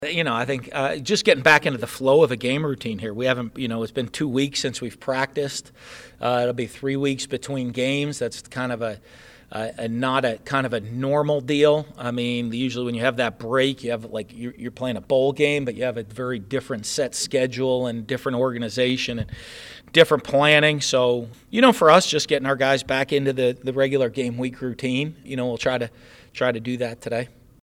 During his press conference on Monday, Dan Mullen talked about how his team is trying to get back into the feel of a ‘standard’ game week.